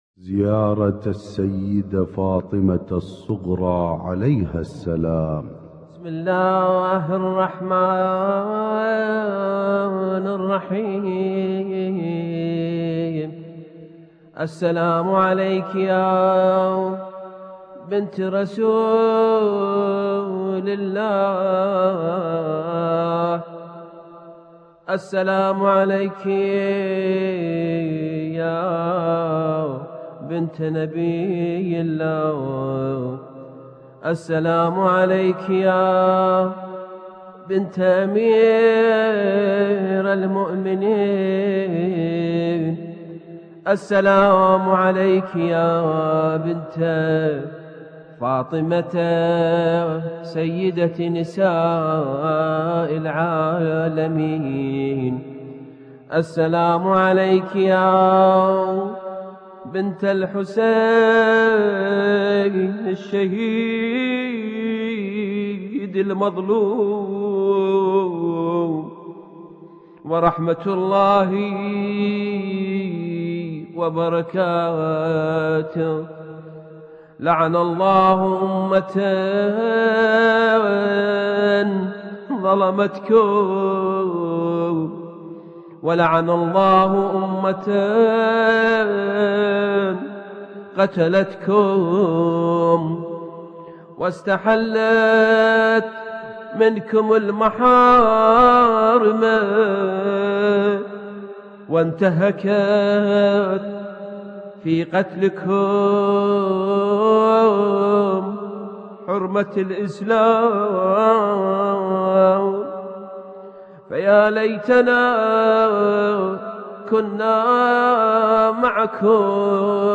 المـكتبة الصــوتيه >> الزيارات >> سلسلة النور